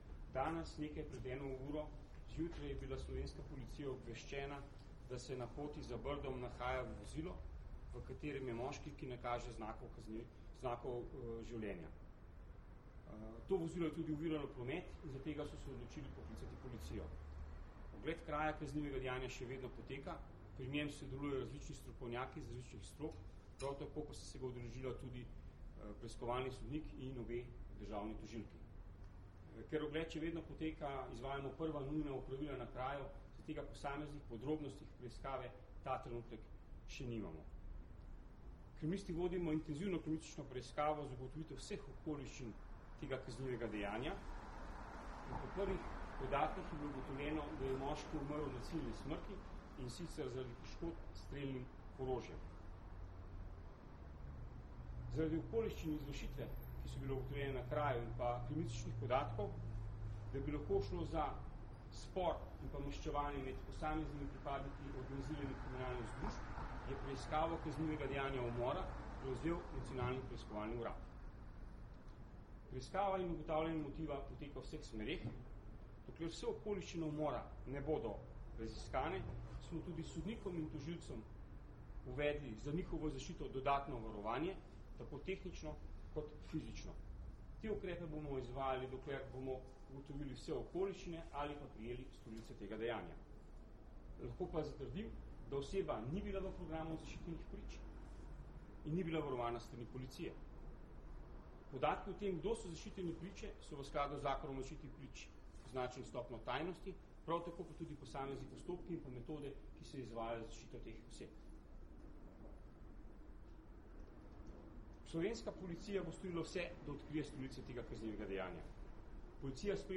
Direktor Uprave kriminalistične policije Damjan Petrič zunaj pred vhodom daje izjavo novinarjem
Zvočni posnetek izjave Damjana Petriča